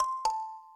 kalimba_c1a.ogg